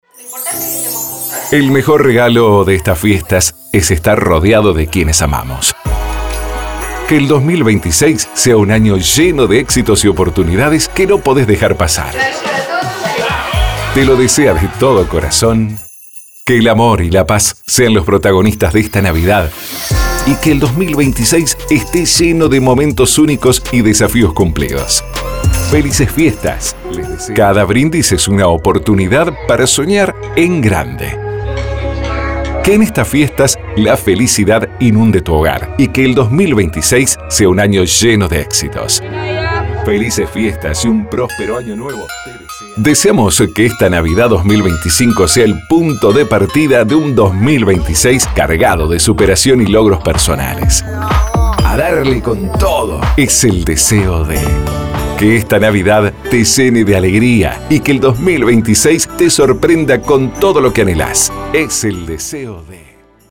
Salutaciones premium para venderle a tus anunciantes o personalizarlas con los datos de tu radio.
✅ Fondos musicales y FX de máxima calidad.